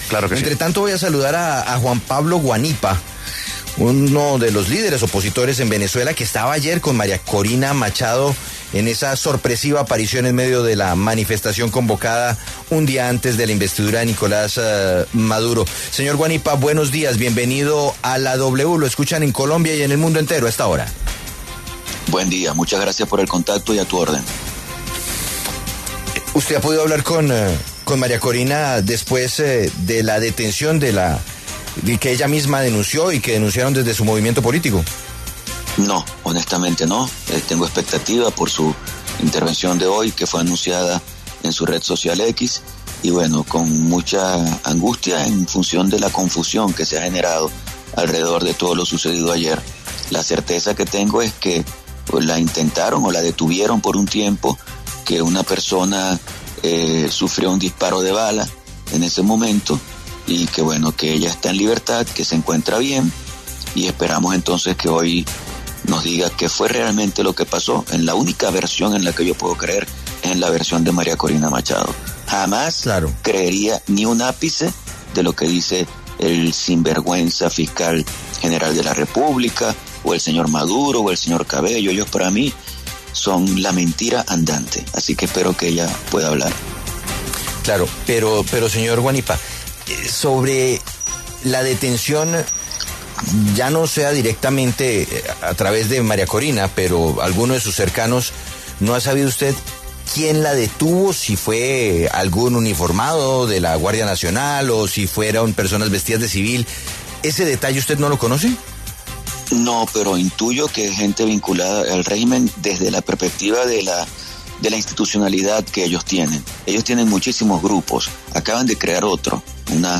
Juan Pablo Guanipa, dirigente opositor venezolano y quien estuvo junto a María Corina Machado en las manifestaciones del 9 de enero de 2025, conversó con La W a propósito de las posesiones de Nicolás Maduro y Edmundo González como presidentes de Venezuela.